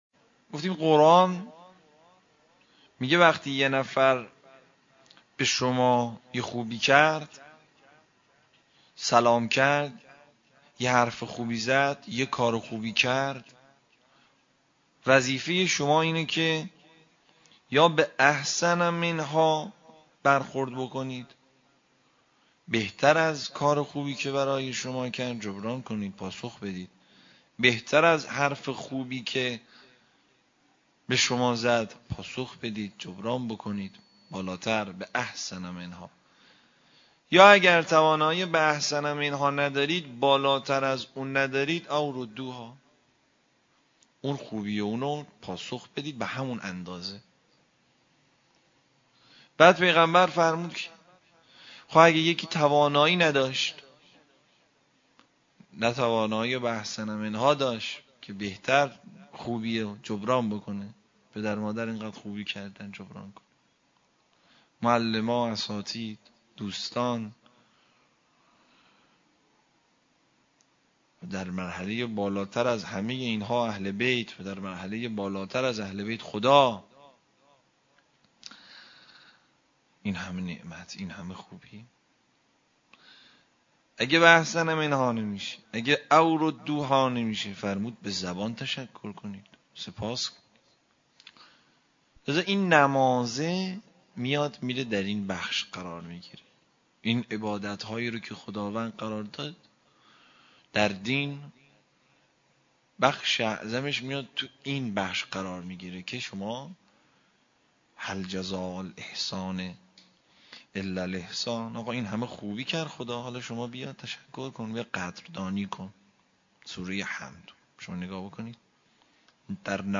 منبر با موضوع جبران1.mp3